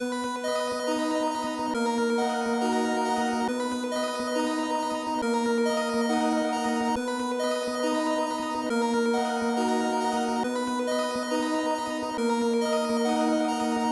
标签： 138 bpm Trap Loops Synth Loops 2.34 MB wav Key : D Audition
声道立体声